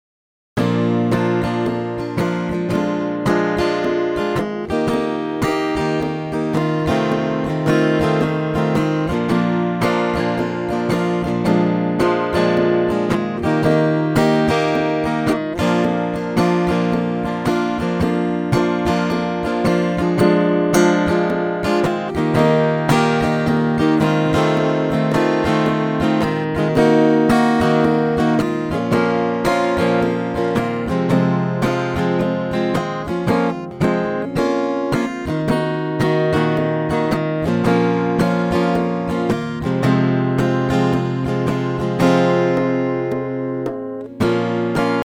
A fun Halloween Song